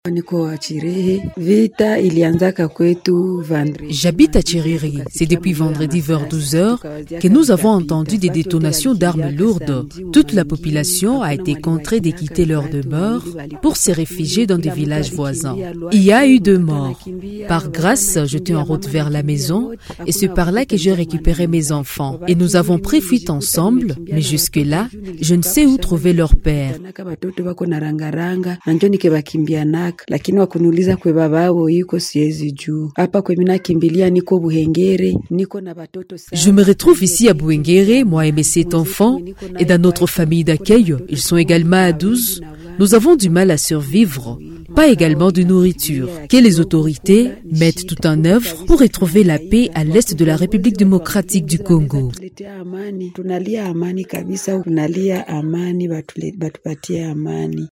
Dans un entretien avec Radio Maendeleo, ces habitants se retrouvent certains dans des villages jugés sécurisés autour de Katana centre ou encore à Mwanda-Katana, d’autres dans des entités autour du centre commercial de Kabamba et dans le village de Kajuchu.